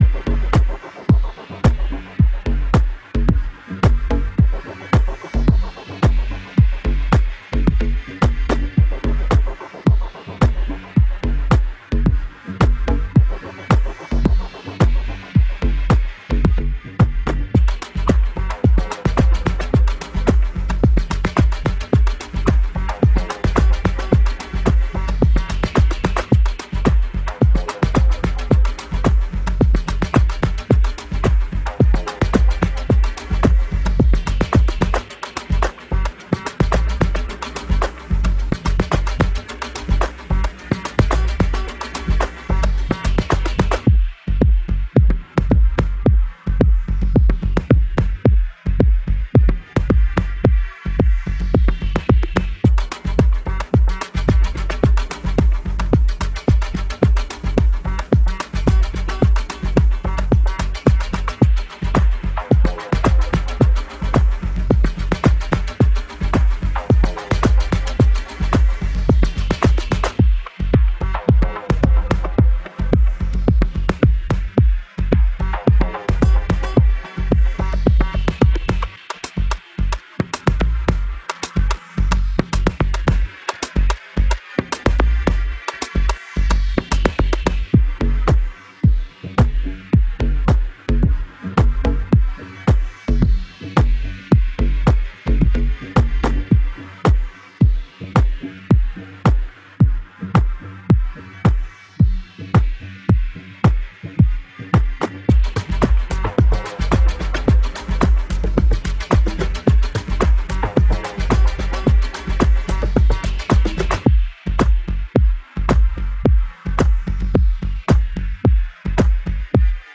House Music, Deep House, Bass Boosted